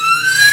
SAW.WAV